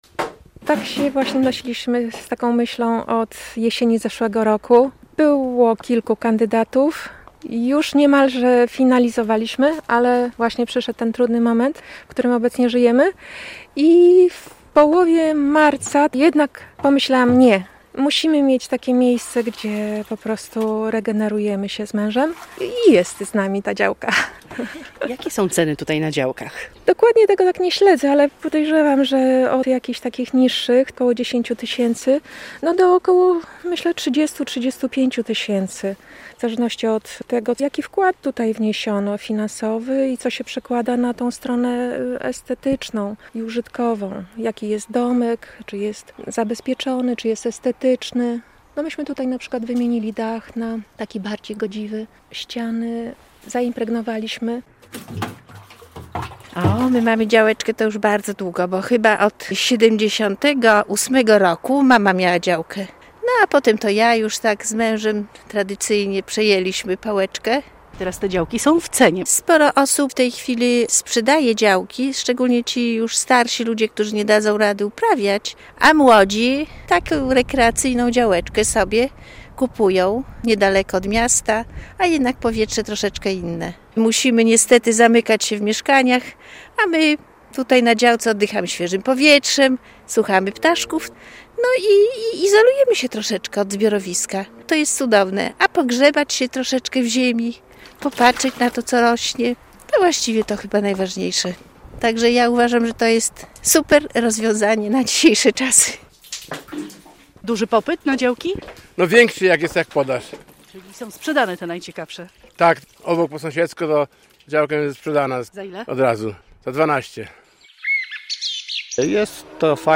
Coraz więcej osób decyduje się na zakup ogródka działkowego - relacja